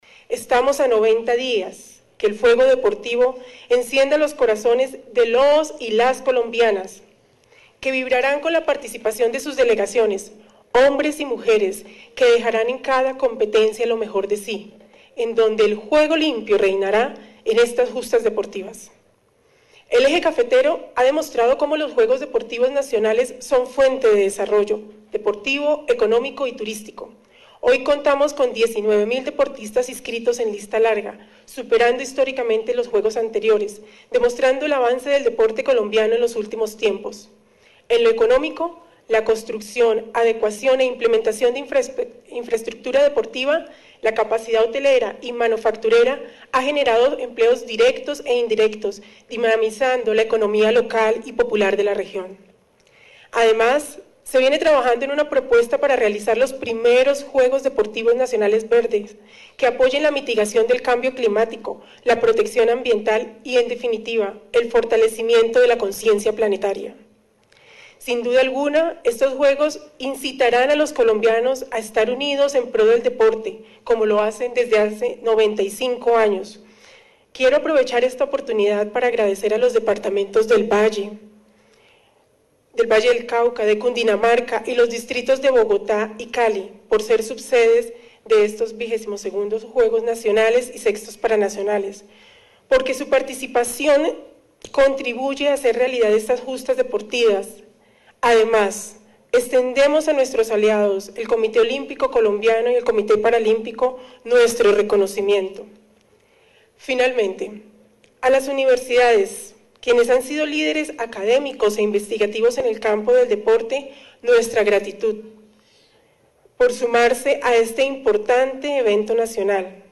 Pereira, 14 de agosto de 2023 ● En Salón Presidente del IDRD de la capital colombiana, se realizó el evento que contó con la presencia de la ministra del Deporte, Astrid Rodríguez.
FULL_MINISTRA_DEL_DEPORTE_ASTRID_BIBIANA_RODRIGUEZ_CORTES.mp3